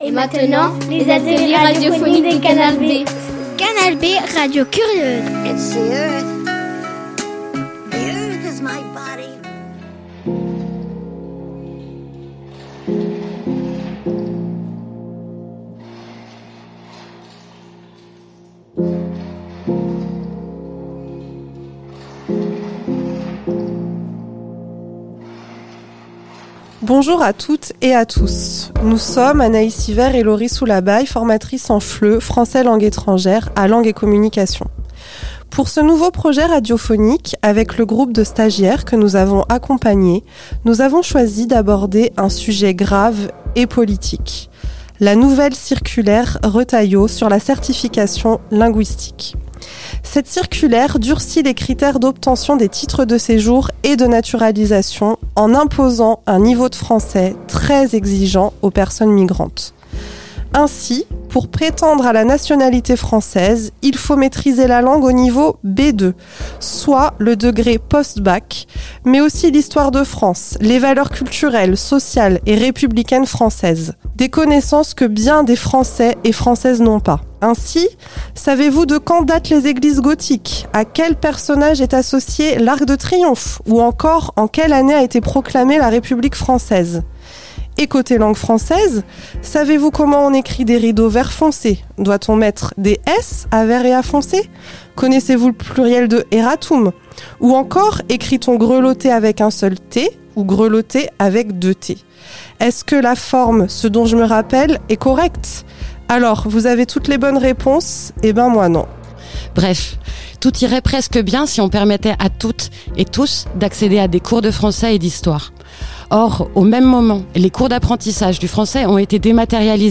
A découvrir : une interview d'une bénévole du MRAP et les parcours de personnes migrantes.